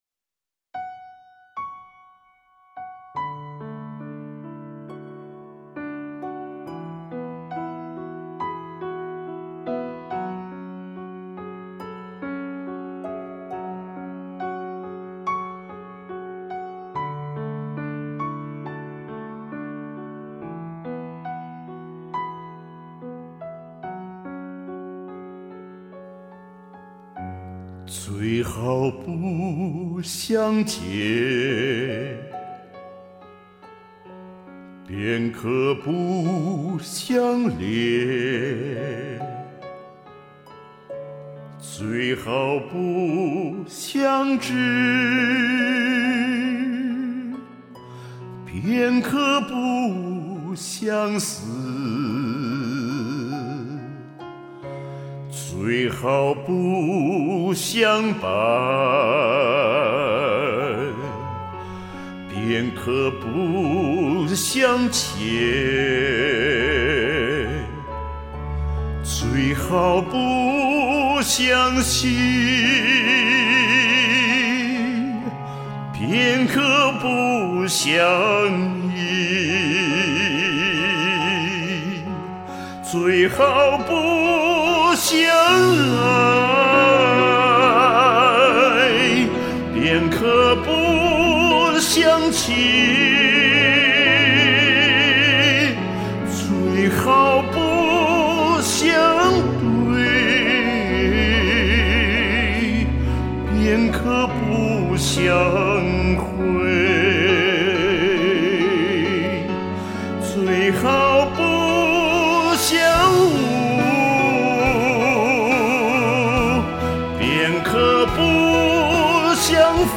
歌声深沉浑厚，情绪既内敛又外放，表现力超强！
音质真棒！！
醇厚的嗓音，美妙的演绎，棒！
哇塞，老乡的中音好漂亮，醇厚磁性魅力四射。